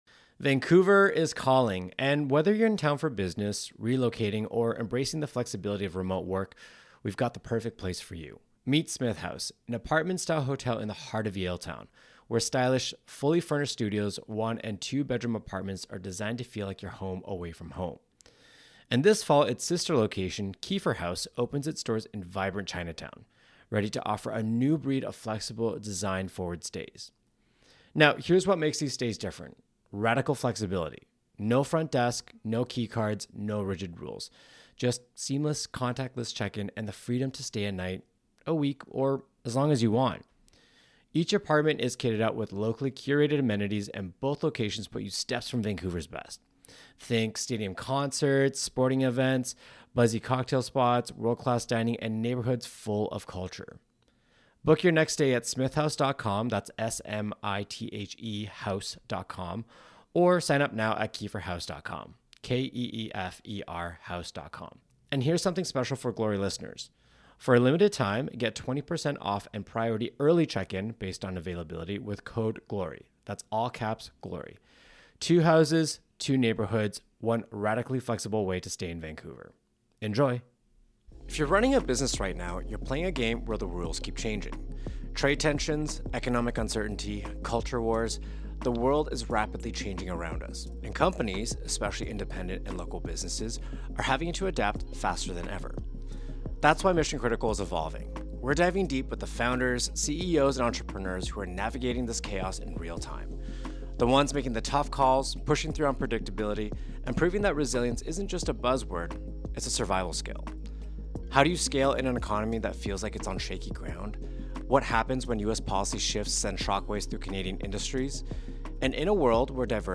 Katzenberg reflects on his third act after Disney & DreamWorks, tracing a lifelong pattern of using technology as a competitive advantage for storytelling.